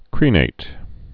(krēnāt) also cre·nat·ed (-nātĭd)